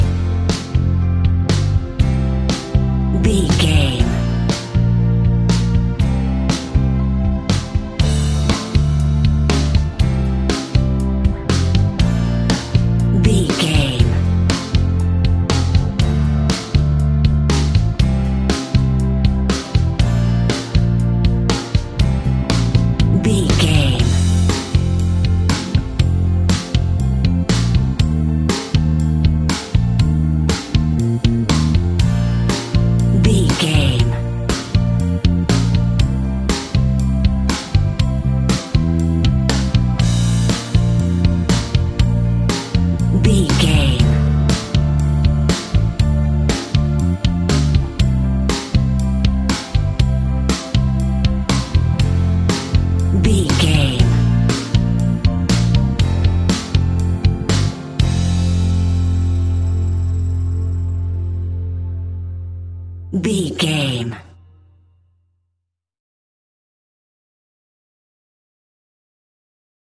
Ionian/Major
pop rock
indie pop
fun
energetic
uplifting
upbeat
groovy
guitars
bass
drums
organ